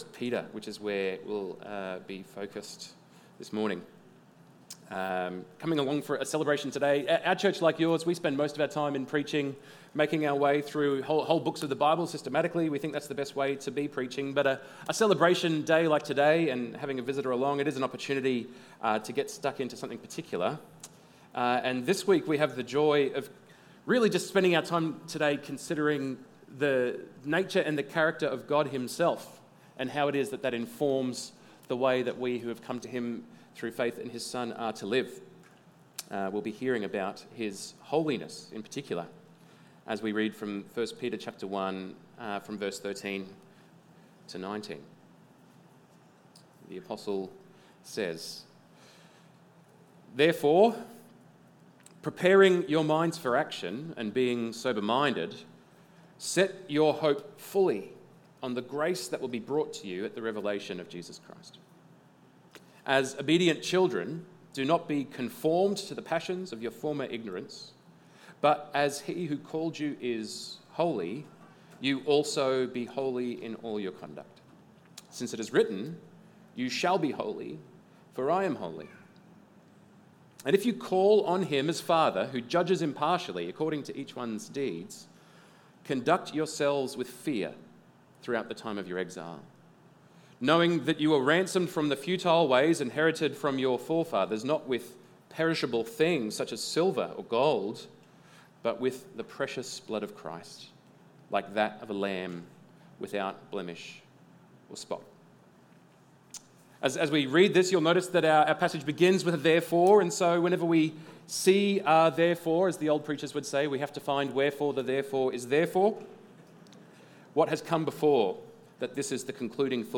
1 Peter 1:13-19 Stand Alone Sermons